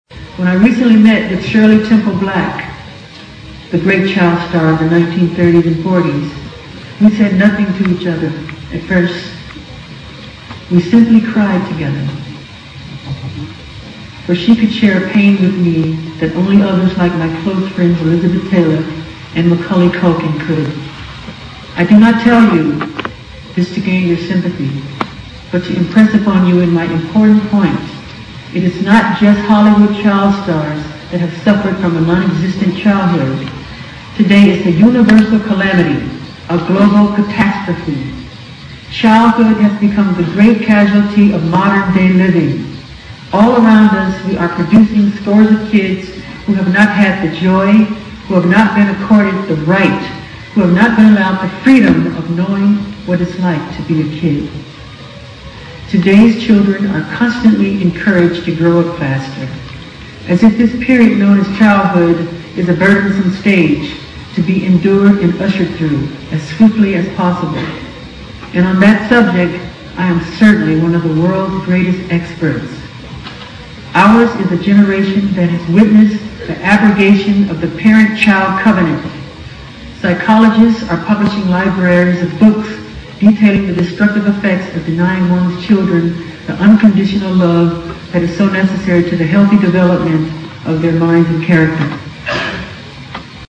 名校励志英语演讲 51:拯救世界 拯救儿童 听力文件下载—在线英语听力室
借音频听演讲，感受现场的气氛，聆听名人之声，感悟世界级人物送给大学毕业生的成功忠告。